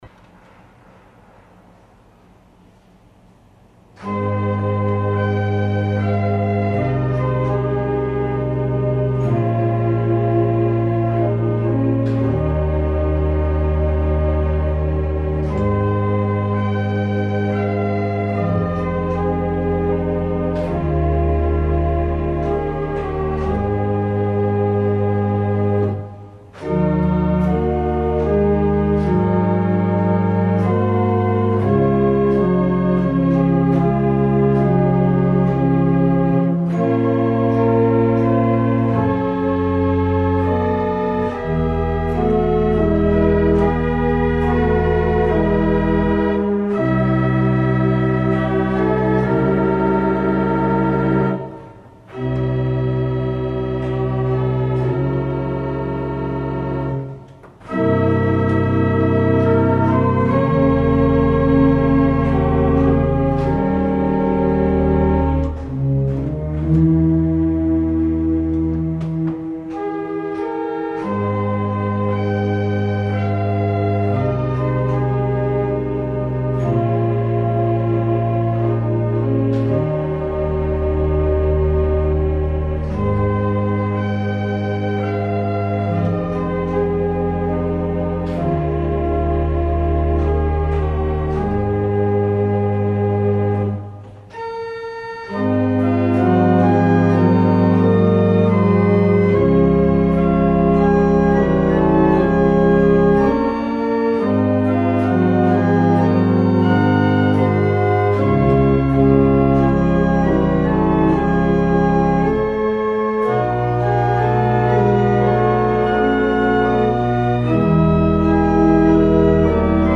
L'Organo Rotelli-Varesi del Monastero di S.Gregorio Armeno- Napoli
Temperamento: Equabile
La registrazione è amatoriale e ha il solo scopo di presentare qualche caratteristica fonica dello strumento.